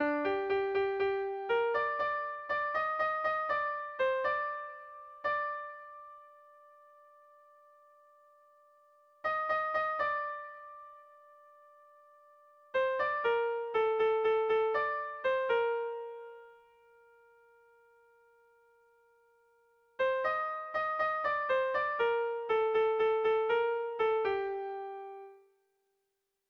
Air de bertsos - Voir fiche   Pour savoir plus sur cette section
Lauko ertaina (hg) / Bi puntuko ertaina (ip)
AB